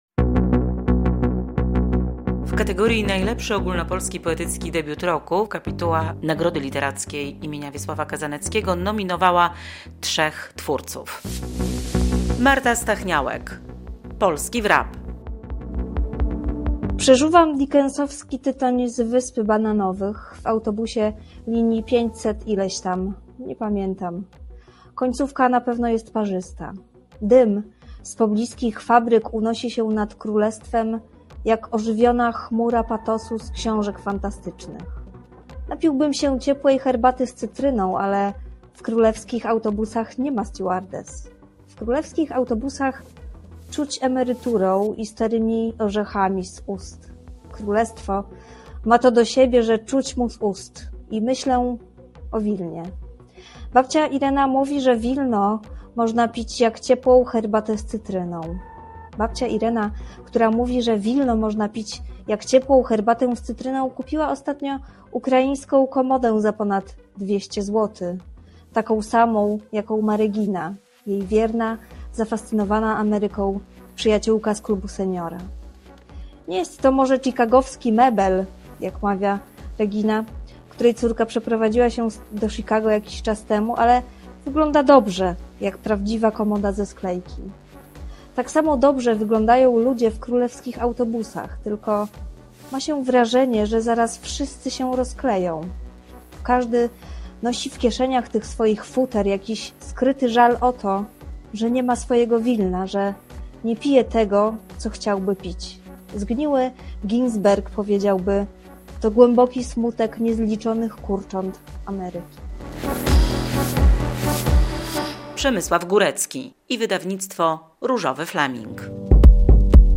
Kandydaci do nagrody im. Kazaneckiego w kategorii debiutu poetyckiego - relacja